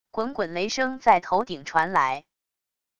滚滚雷声在头顶传来wav音频